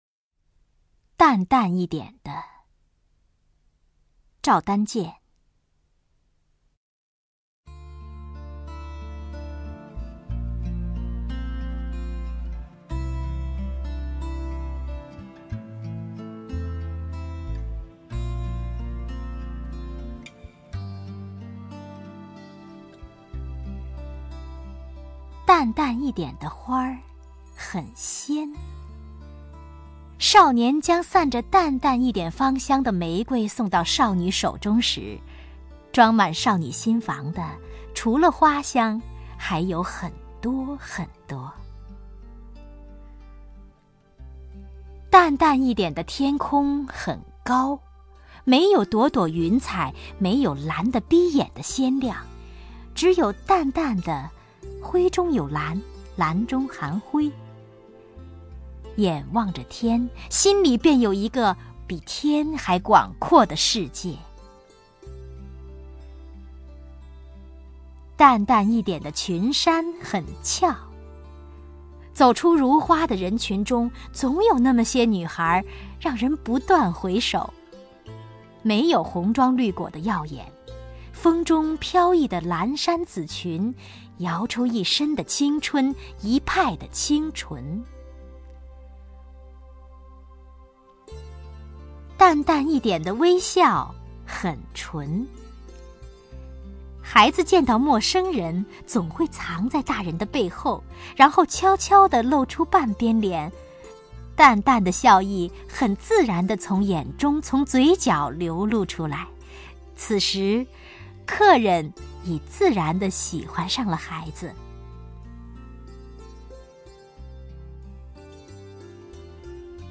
王雪纯朗诵：《淡淡一点的……》(赵丹健)　/ 赵丹健
名家朗诵欣赏 王雪纯 目录